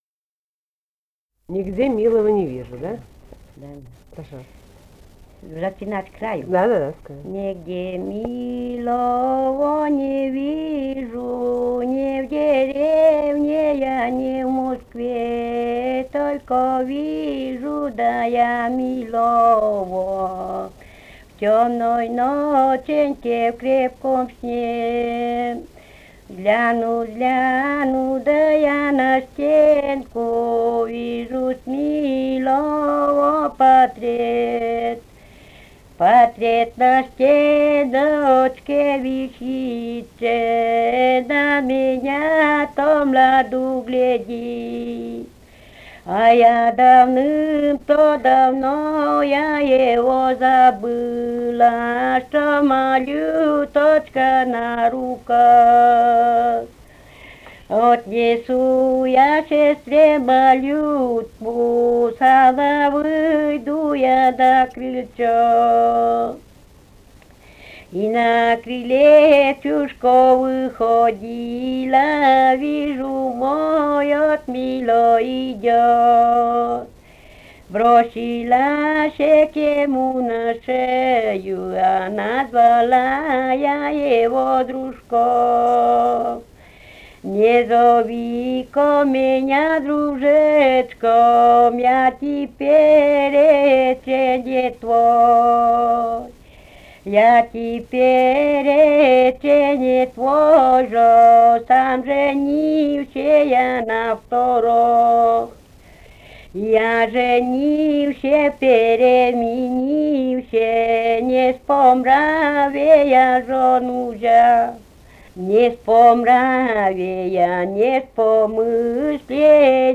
Живые голоса прошлого 132. «Нигде милого не вижу» (лирическая).